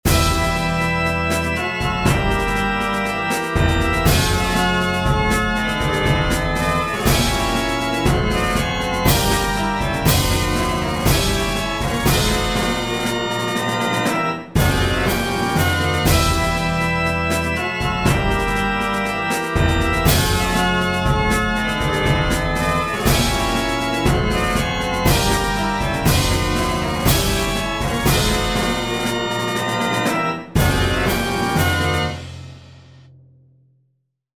Groove in 8edo
8edo_groove.mp3